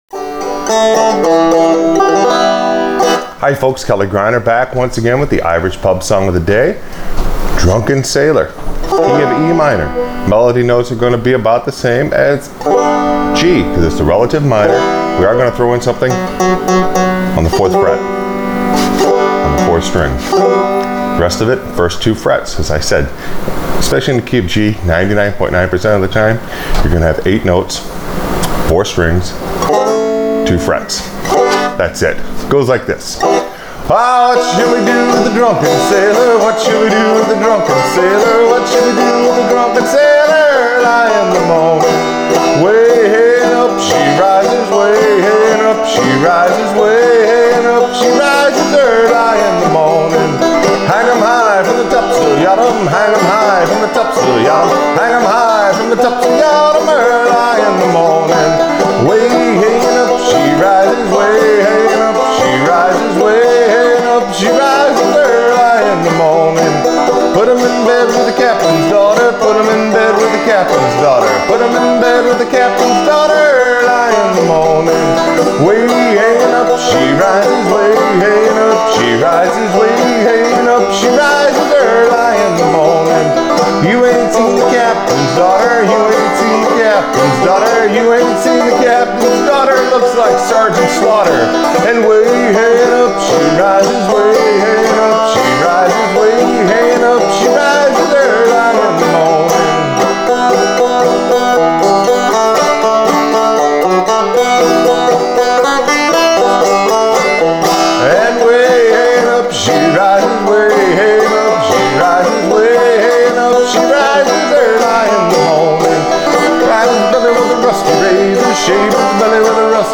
Irish Pub Song Of The Day – Drunken Sailor on Frailing Banjo
The Key of Em is the “relative minor” of the G, so your melody notes are going to be in same places you find them in the Key of G.